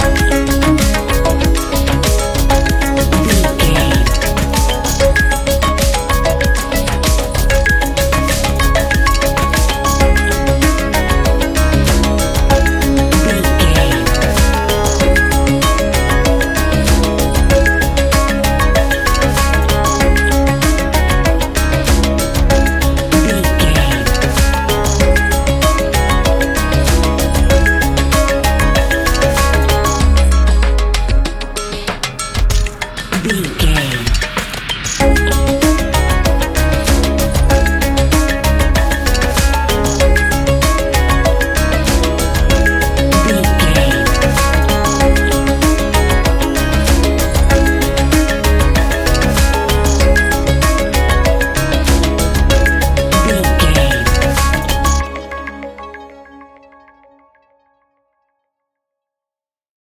Ionian/Major
C♭
electronic
techno
trance
synths